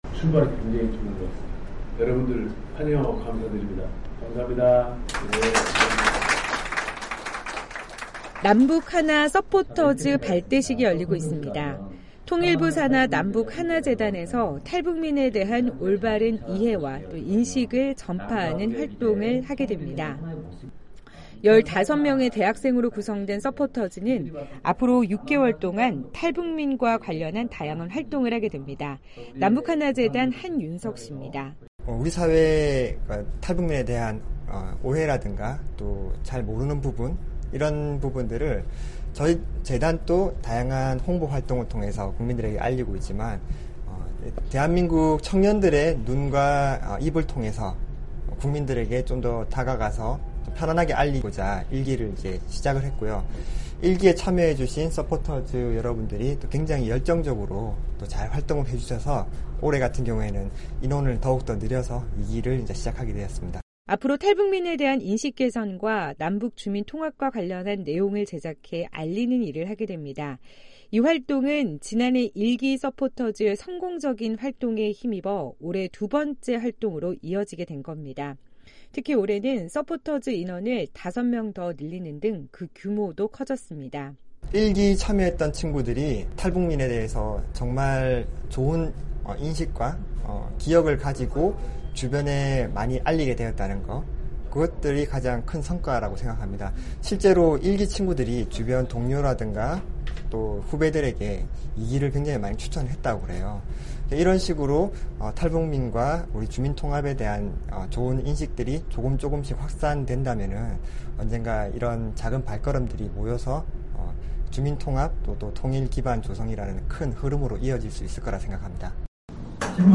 13일 오전 서울 마포구 남북하나재단에서 '남북하나서포터즈' 제2기 발대식이 열렸다.